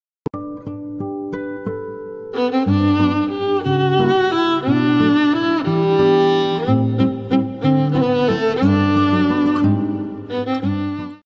Lunch Box Sound Effects Free Download